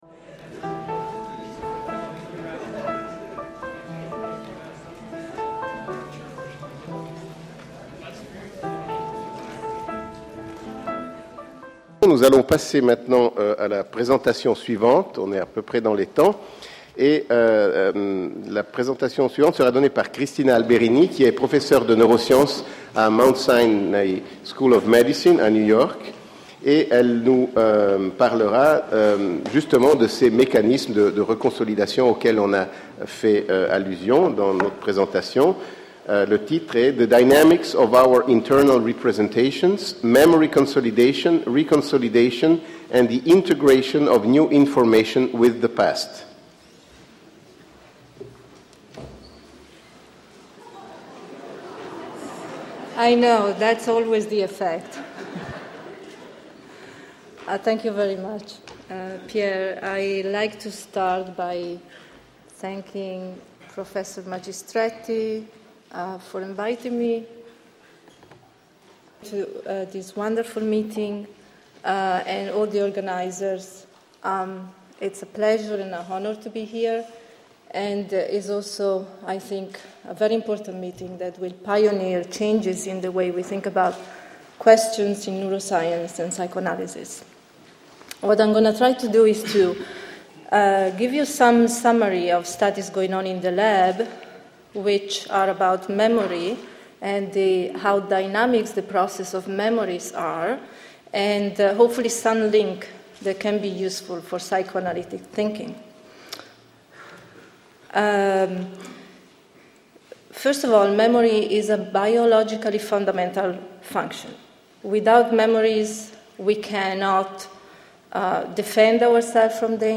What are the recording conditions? Colloque neurosciences et psychanalyse, le 27 mai 2008